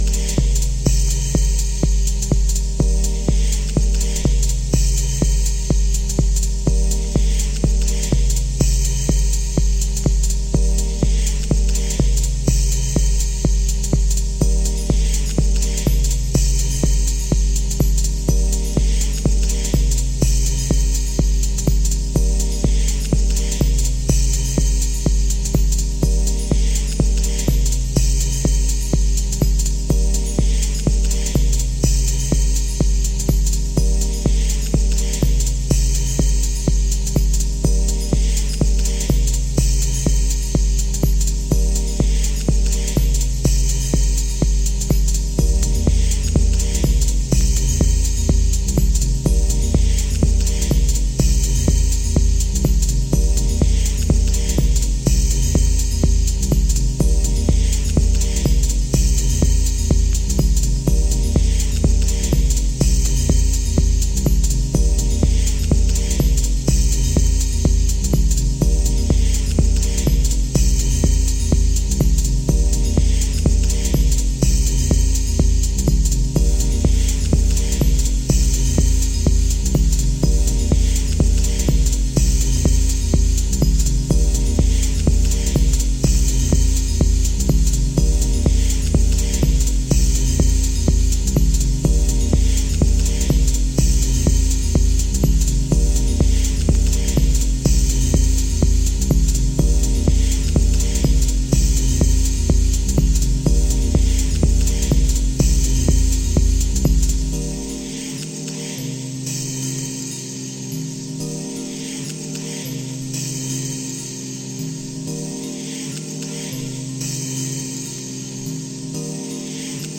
Techno Dub